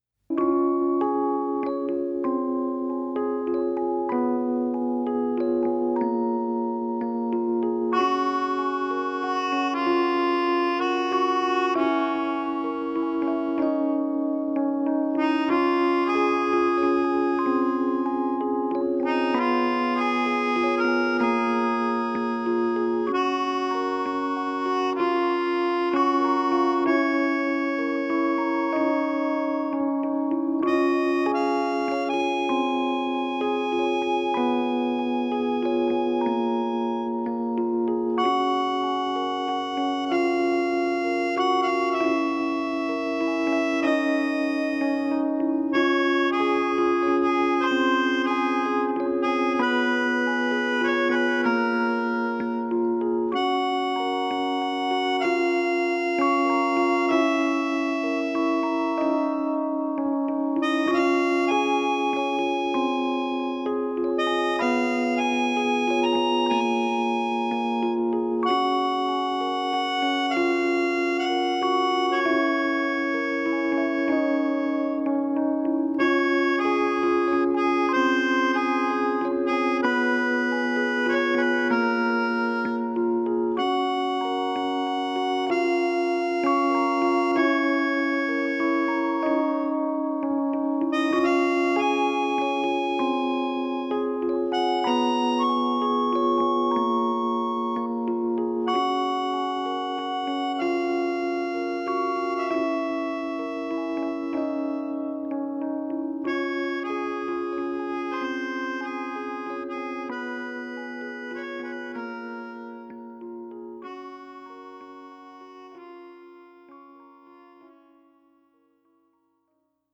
Genre: Indie, Alternative Rock, Original Soundtrack